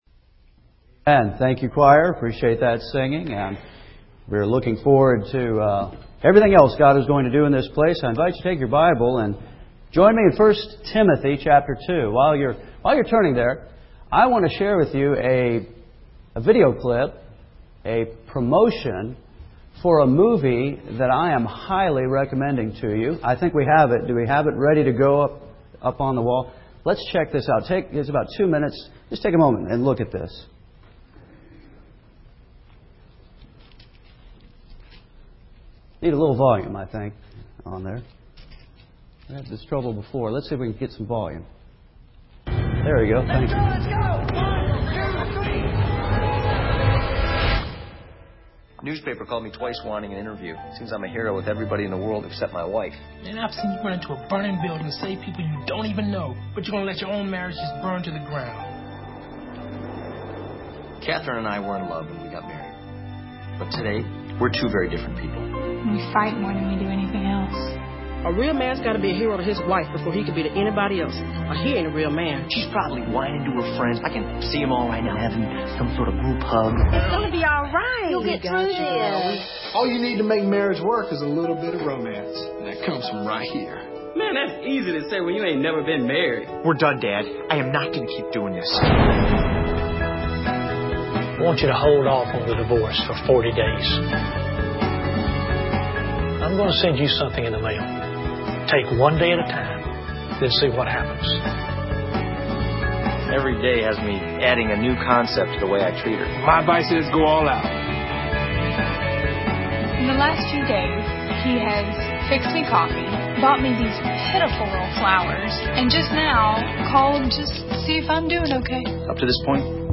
First Baptist Church Henderson, KY